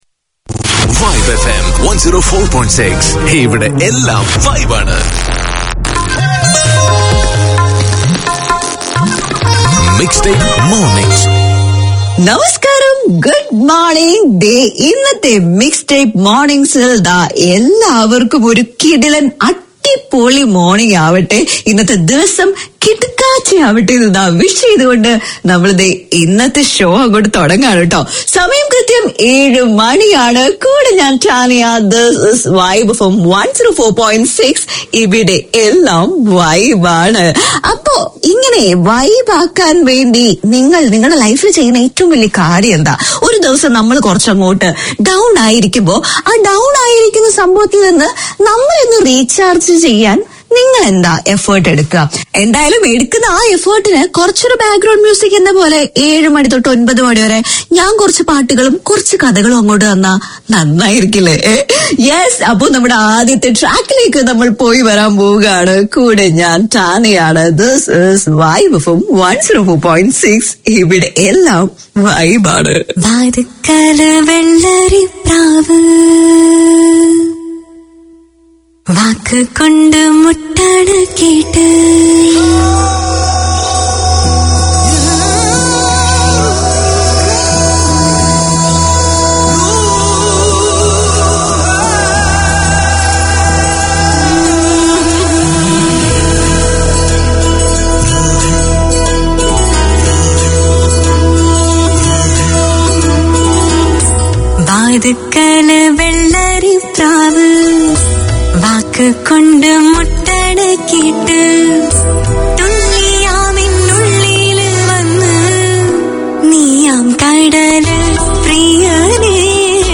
A celebration of Malayalam language, literature, music and culture; Malayalam FM presents three weekly programmes. Hear dramas and stories based on Malayalam songs on Fridays, film and music on Saturdays, and enjoy a talk-based show featuring discussions and interviews on the Sunday edition.